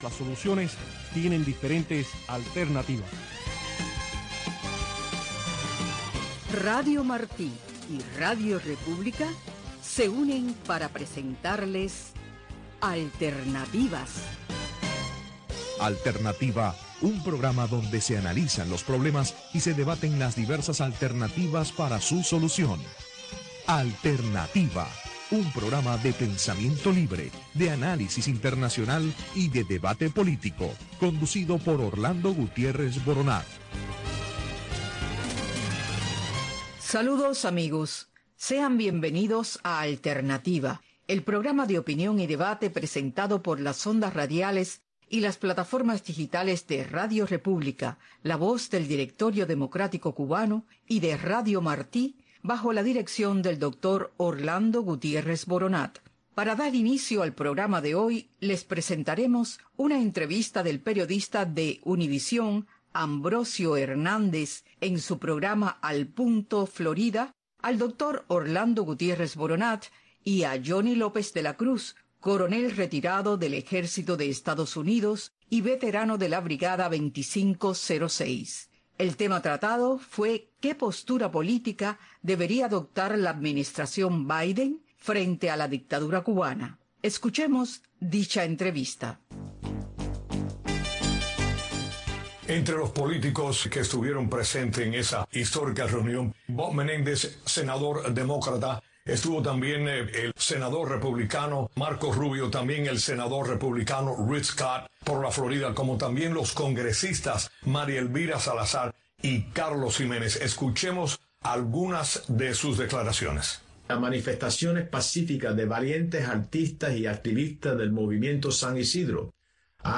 El programa radial Alternativa es un programa de panel de expertos